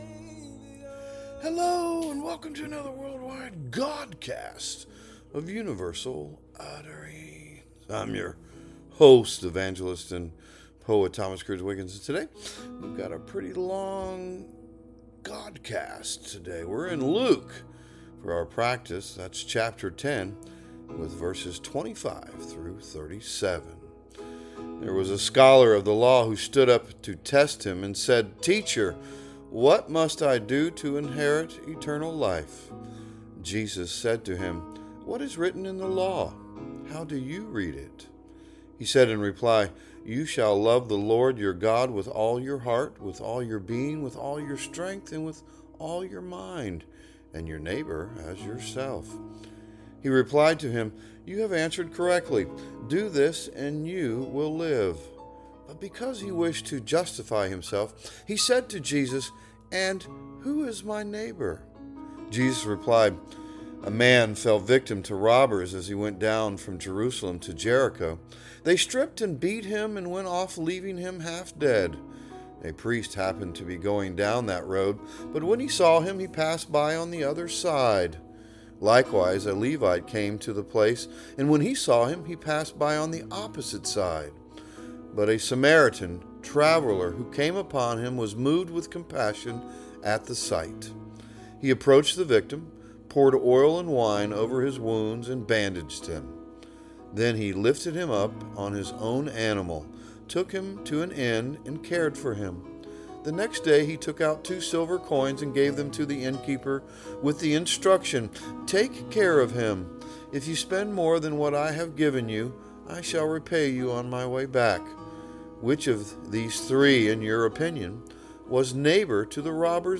A Godcast